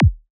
Here You can listen to some of the Kicks included in the sample library:
• Versatile and Dynamic: Whether you're producing trance, psytrance, or any other high-energy electronic music, these kicks will add that essential punch to your tracks.
KICK-XI-195.wav